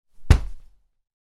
جلوه های صوتی
دانلود صدای افتادن بدن از ساعد نیوز با لینک مستقیم و کیفیت بالا
برچسب: دانلود آهنگ های افکت صوتی انسان و موجودات زنده